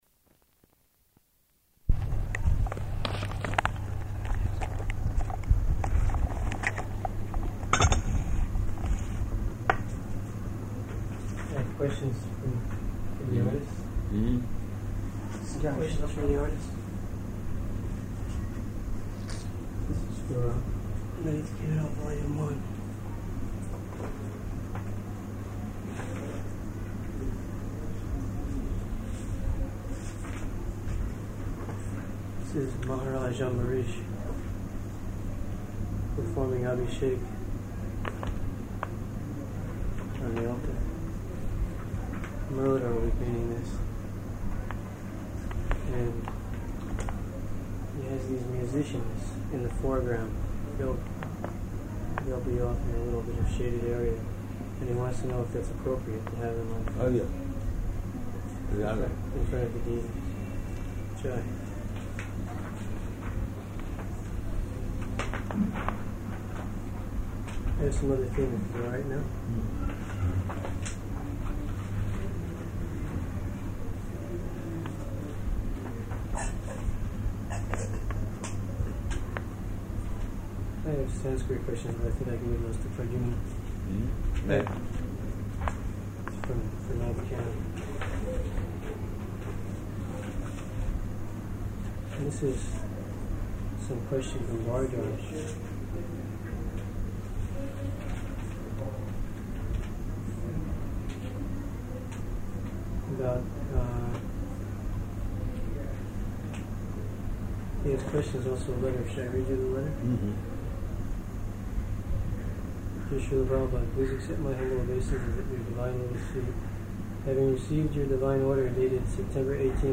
-- Type: Conversation Dated: December 20th 1976 Location: Bombay Audio file